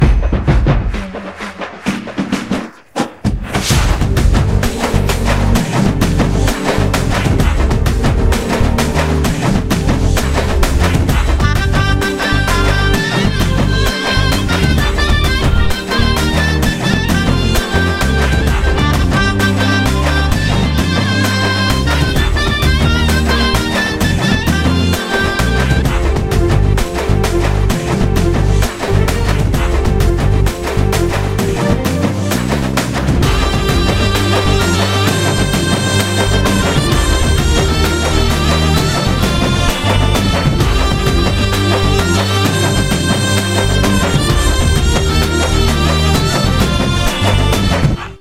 without dialogues and fight sounds